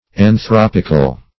Anthropic \An*throp"ic\, Anthropical \An*throp"ic*al\, a. [Gr.
anthropical.mp3